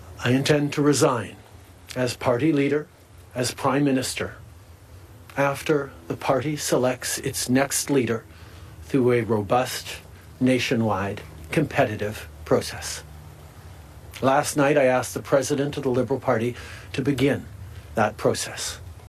Mr Trudeau made the announcement in the last half an hour: